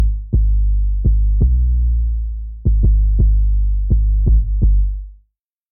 120120 拉下黑布条
描述：在教室里拉下一块黑板（在轮子上滑动）。 放大H4n
标签： 车轮 教学幻灯片 黑板 学校 教室
声道立体声